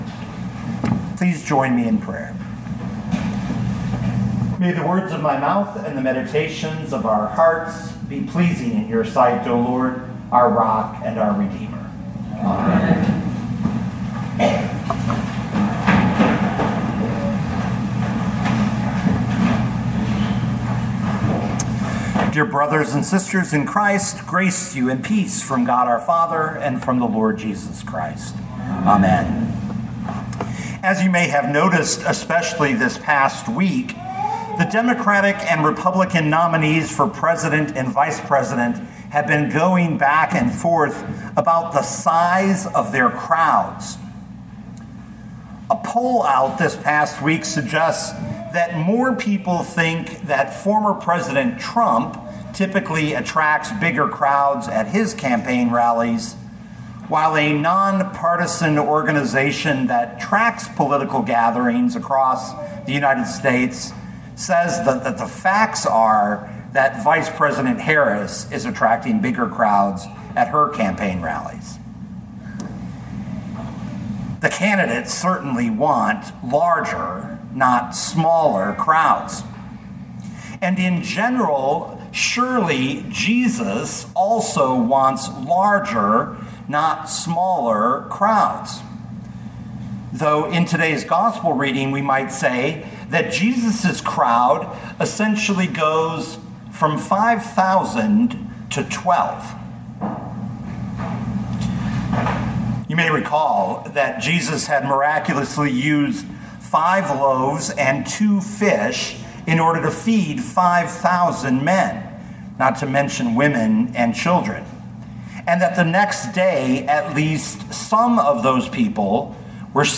2024 John 6:51-69 Listen to the sermon with the player below, or, download the audio.